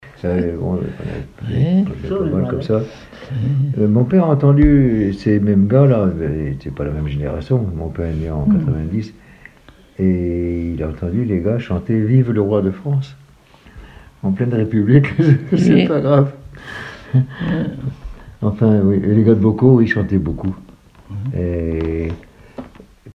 Catégorie Témoignage